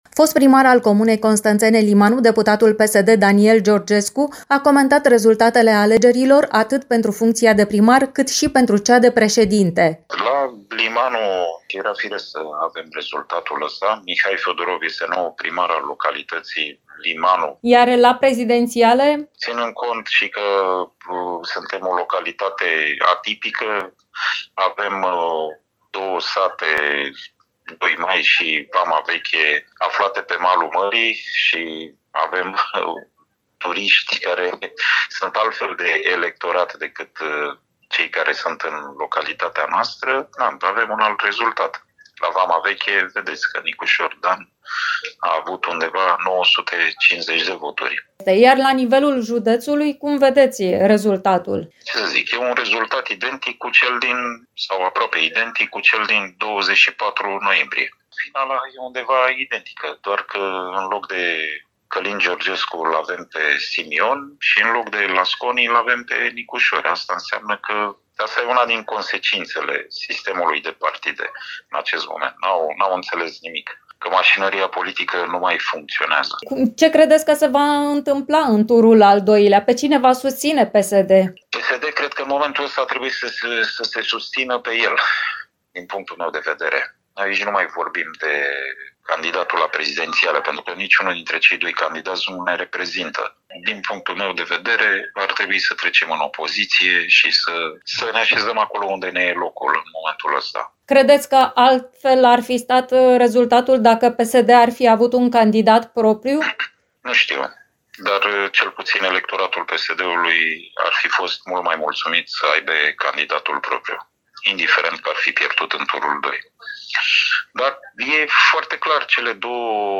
Cum comentează politicienii constănțeni aceste rezultate, aflăm din reportajul următor